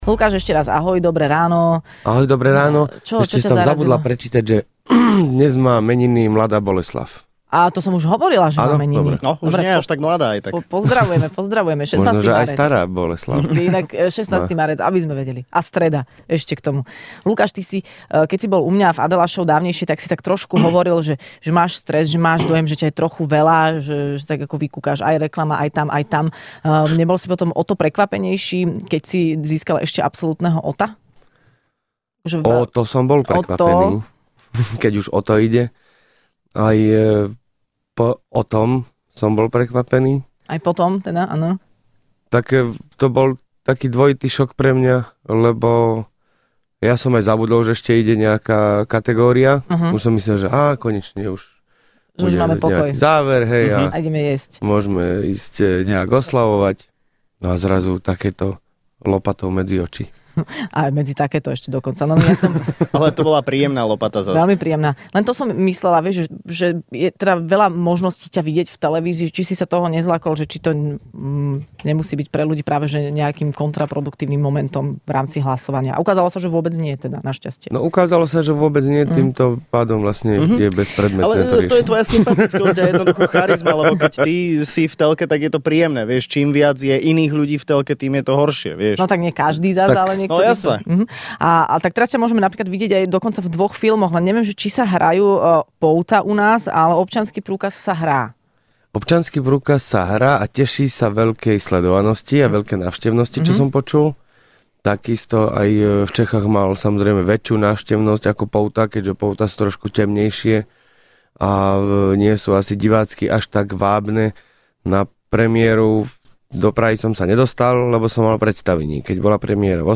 Hosťom v Rannej šou bol absolútny víťaz ocenenia OTO Lukáš Latinák.